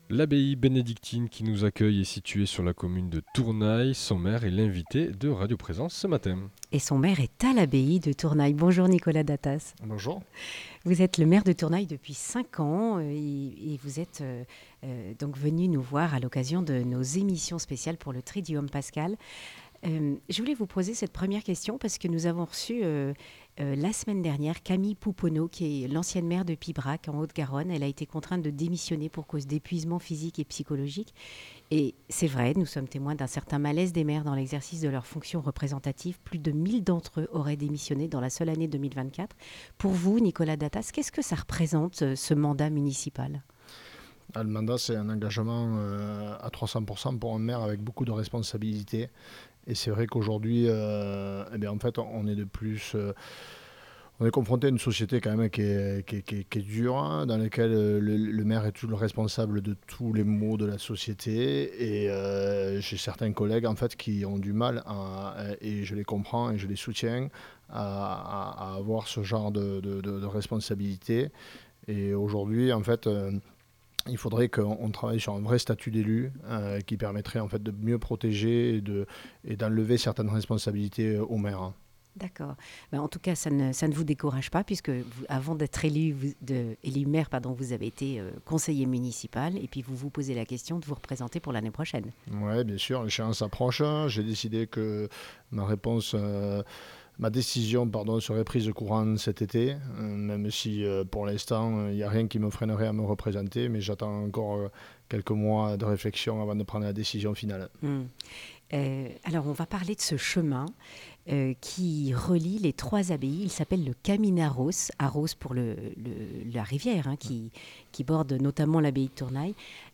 Nicolas Datas, maire de Tournay, présente l’activité de sa commune et le projet de sentier entre les trois abbayes : Tournay, l’Escaladieu et Saint Sever de Rustan.